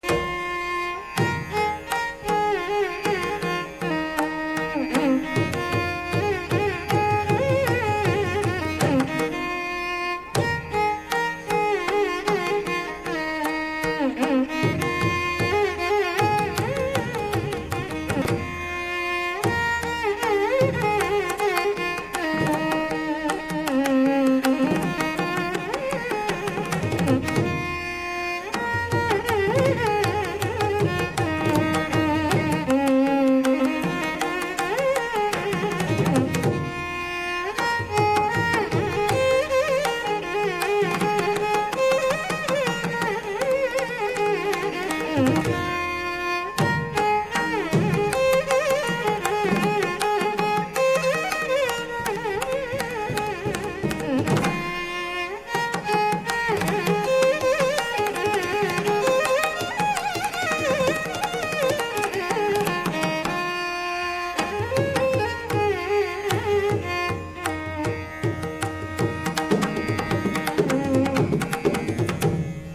violin
mrdangam
tambura
Pallavi begins in the purva anga (purvānga) .
Rāga Kanada